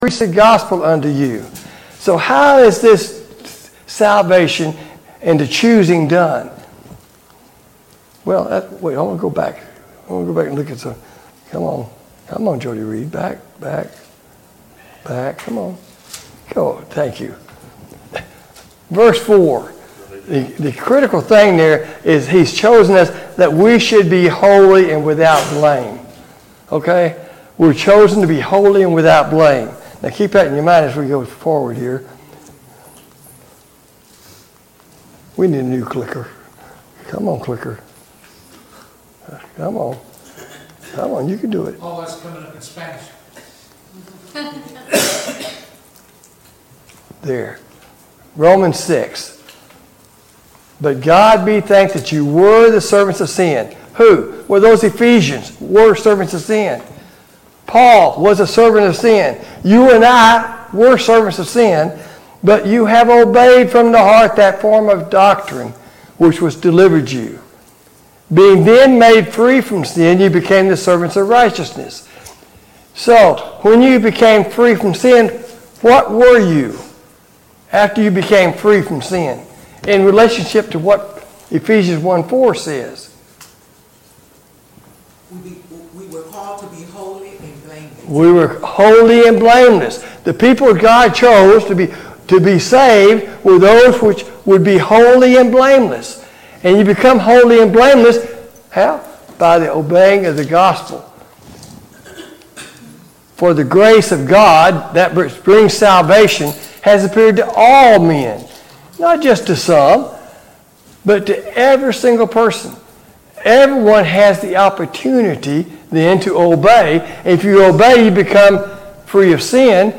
God's Scheme of Redemption Service Type: Sunday Morning Bible Class « Study of Paul’s Minor Epistles